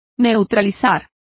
Complete with pronunciation of the translation of counteracting.